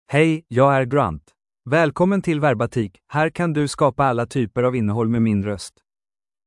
GrantMale Swedish AI voice
Grant is a male AI voice for Swedish (Sweden).
Voice sample
Listen to Grant's male Swedish voice.
Grant delivers clear pronunciation with authentic Sweden Swedish intonation, making your content sound professionally produced.